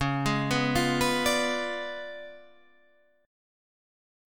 Db7b9 chord